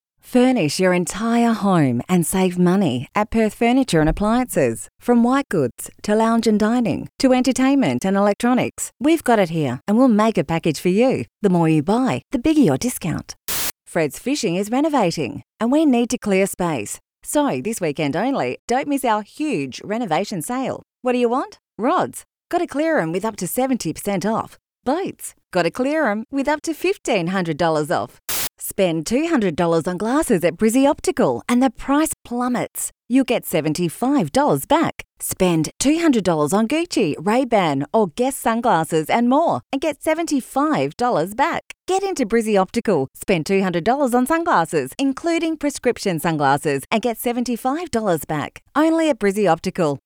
• Soft Sell
• Versatile